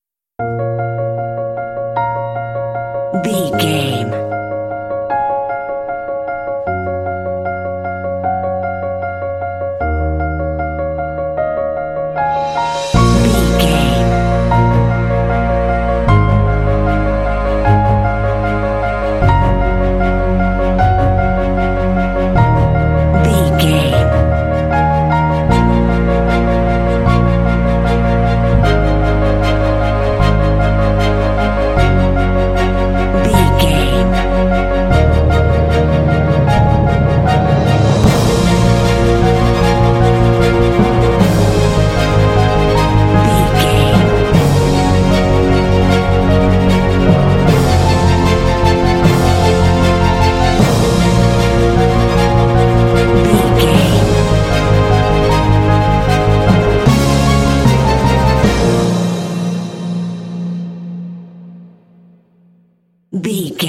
Epic / Action
Fast paced
Aeolian/Minor
B♭
piano
strings
orchestra
cinematic